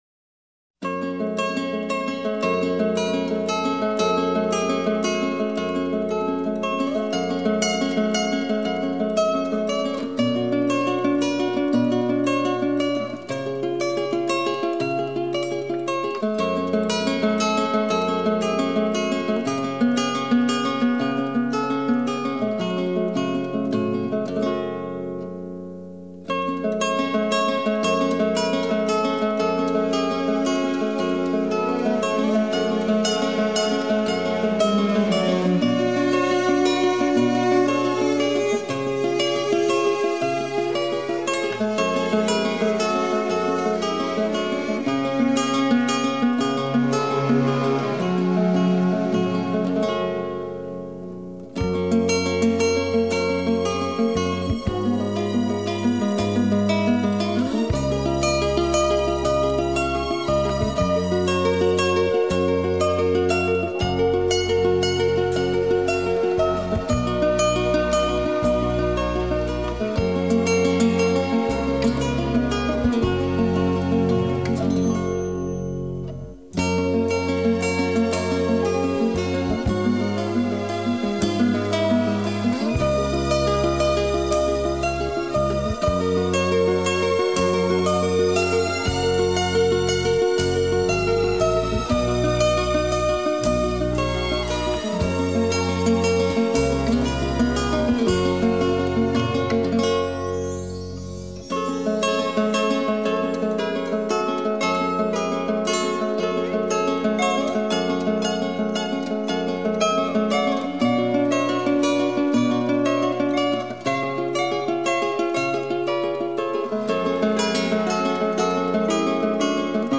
语言：纯音乐